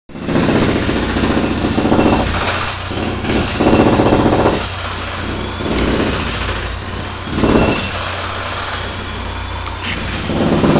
Jack Hammer
This is the sound inside my house (from upstairs, even) right now. They dig up a trench around the perimeter and install drain tile, sump pump, water guard, etc and guarantee it for life.
jackhammer.wav